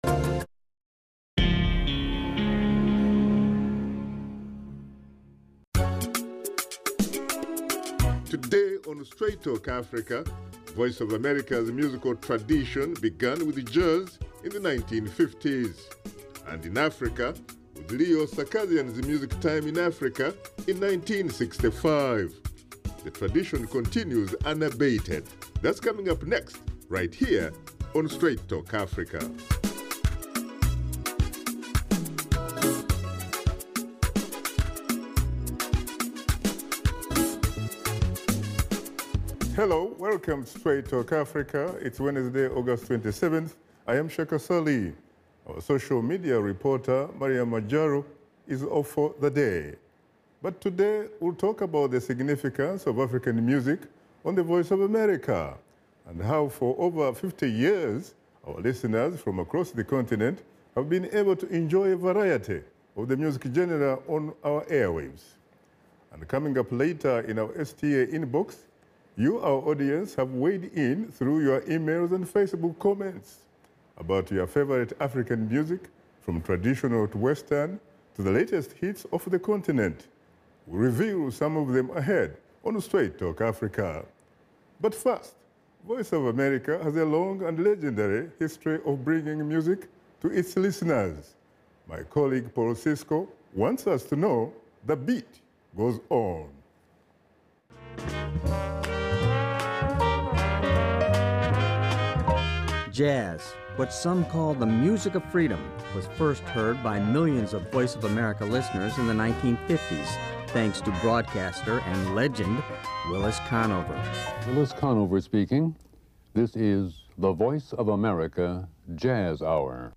Washington Studio Guests